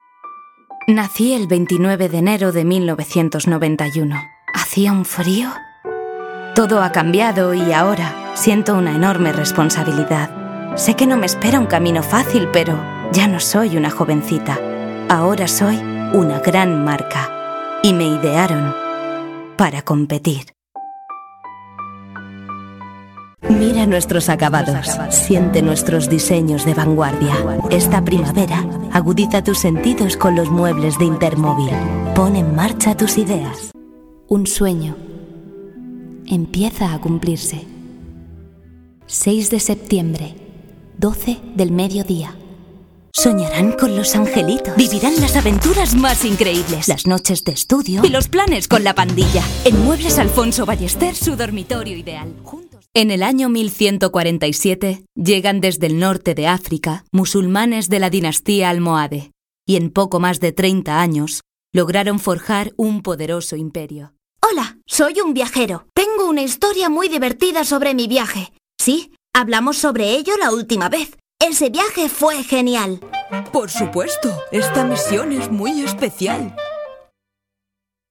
Locutora, home studio, actriz
Sprechprobe: Sonstiges (Muttersprache):
Voice over, home studio, actress